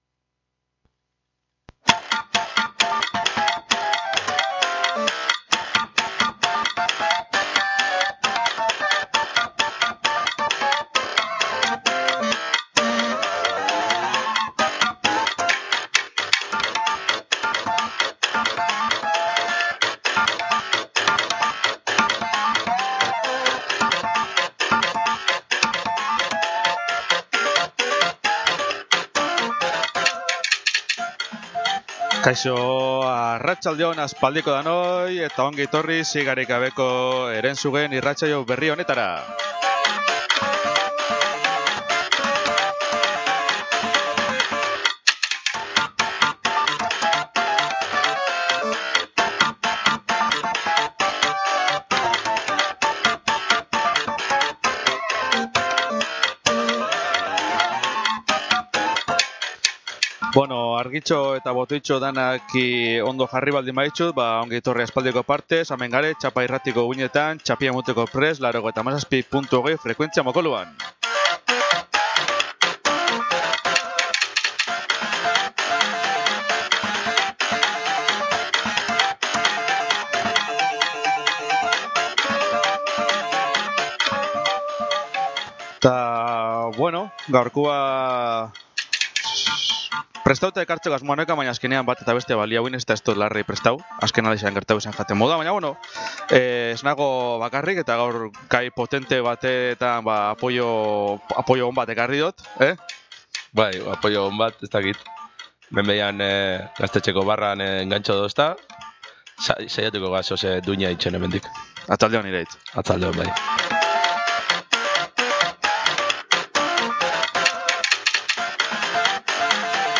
Bergara-ko Maijoku Elkartea-k mahai jokuen inguruan Txapa irratian eginiko irratsaioa. Bertan, nagusiki, mahai jokuak azalduko dira, beraien funtzionamendua, jolasteko era eta dituzten onurak aipatuz.